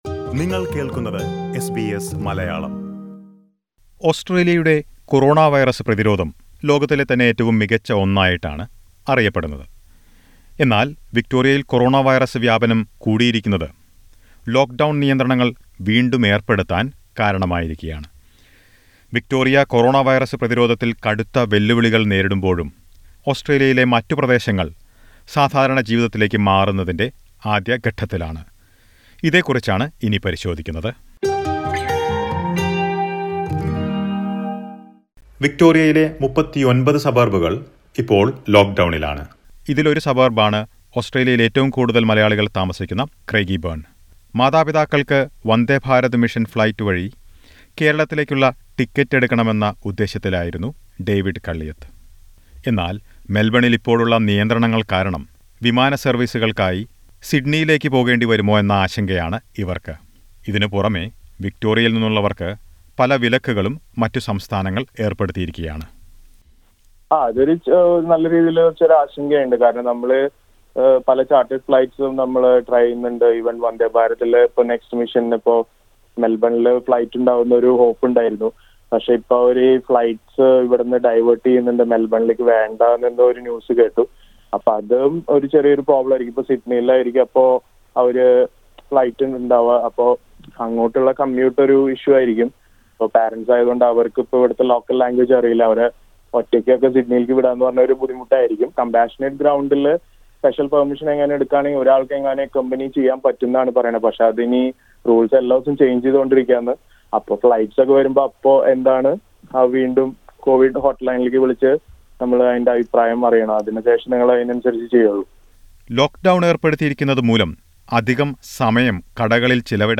But how far has life returned to normalcy in other cities of Australia? Listen to some comments from Malayalees living in different parts of the country.